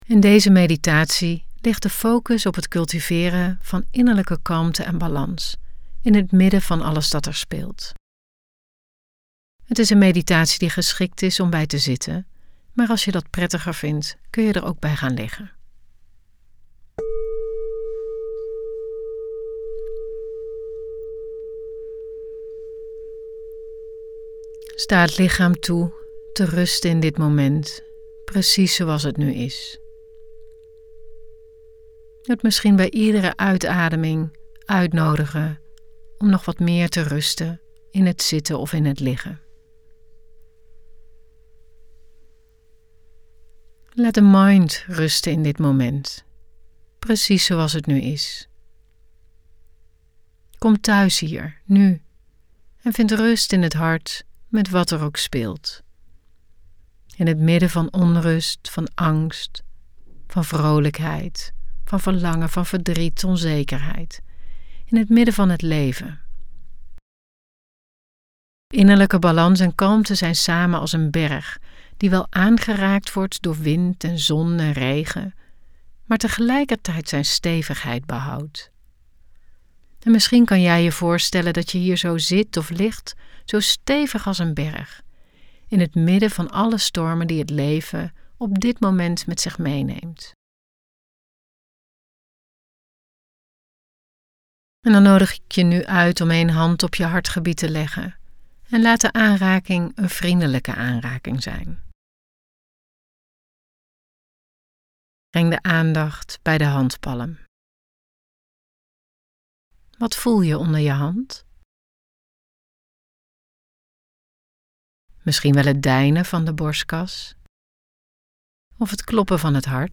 Meditatie: Innerlijke kalmte en balans